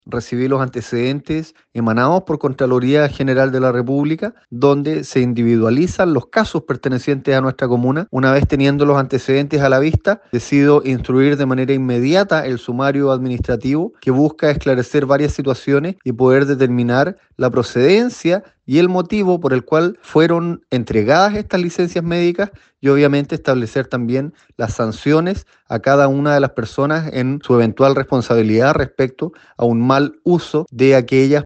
En el caso de Río Negro, su alcalde, Sebastián Cruzat, señaló que tras recibir los antecedentes de dos funcionarias del municipio y cuatro del DAEM de esa comuna que incurrieron en la falta, se instruyó un sumario.